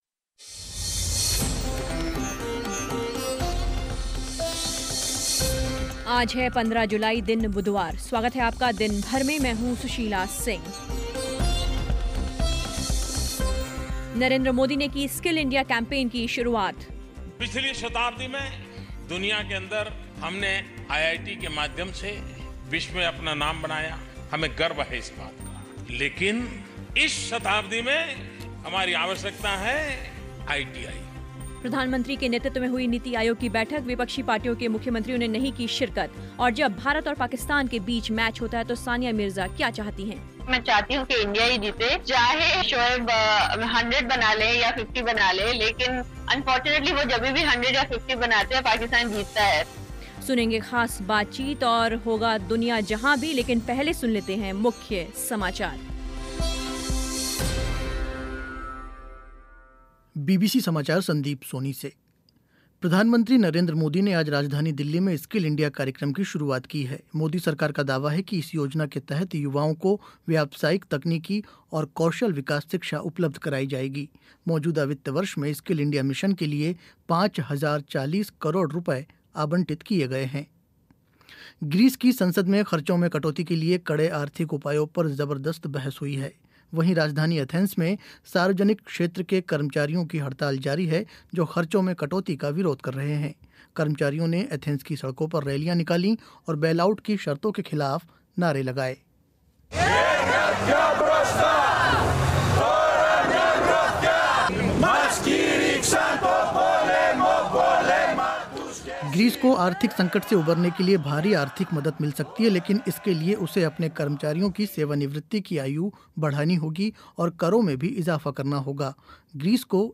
भारत और पाकिस्तान के मैच होता है तो सानिया मिर्ज़ा भारत की जीत की मनोकामना करती है, सुनिए ख़ास बातचीत